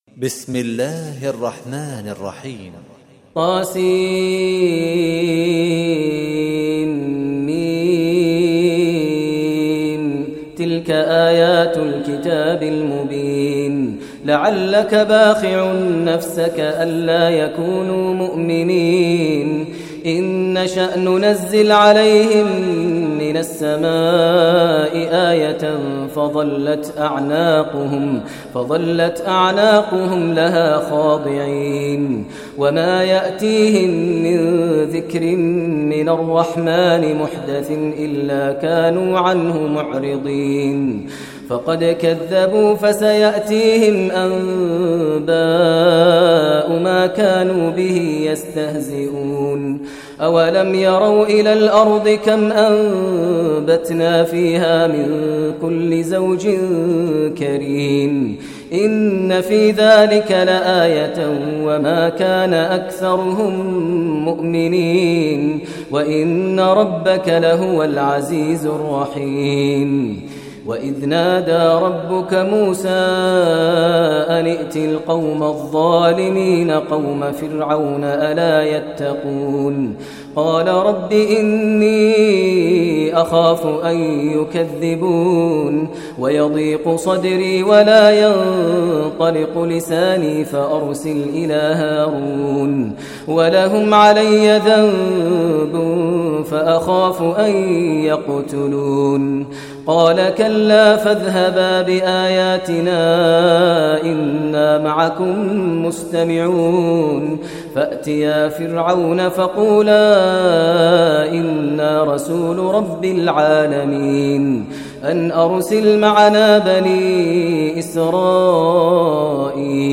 Surah Shuara Recitation by Maher al Mueaqly
Surah Ash Shuara, listen online mp3 tilawat / recitation in Arabic. recited by Imam e Kaaba Sheikh Maher al Mueaqly.